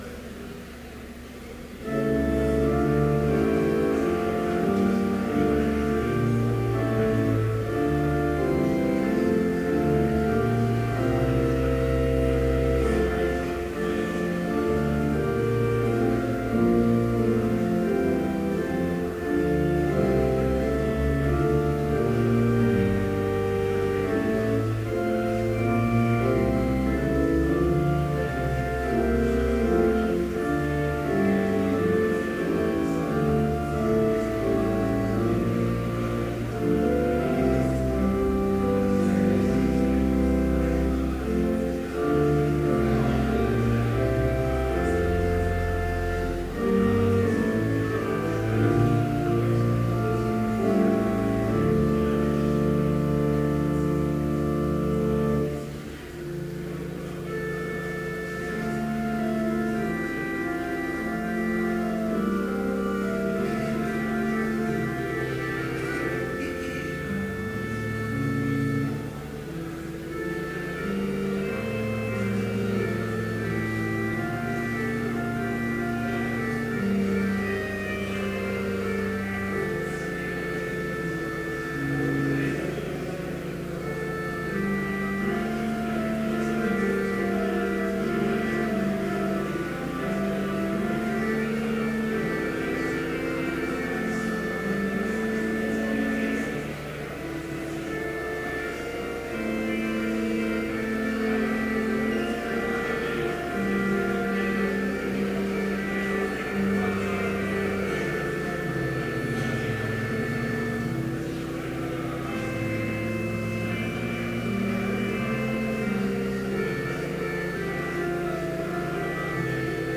Complete service audio for Chapel - March 17, 2014